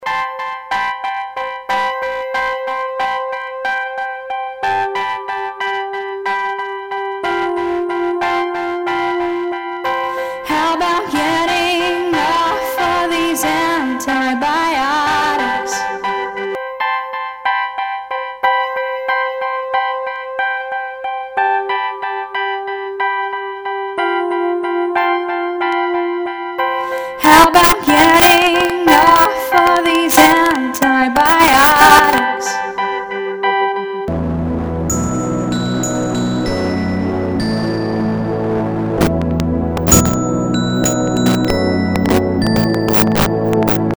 It is still very buggy and I hear a lot of cracks and short bursts of really bad distortion in the sound, but the concept seems to work very well!
I've run a test with some of the most difficult tracks from the Torture Test (original_wave.flac), clipped at 1/4th of its peak level, and - except for a really really HORRIBLE amount of clipping and static, which really needs to be fixed to make this usable - except for that, it reproduces the original sound perfectly - with apparently NO DISTORTION at all.
Short teaser (first clipped sound, then repaired sound):